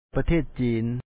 pathèet ciin China